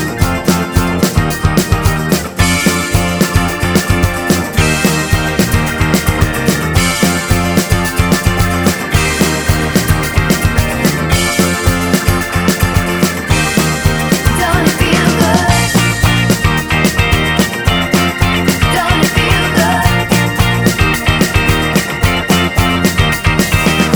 No Electric Guitar Pop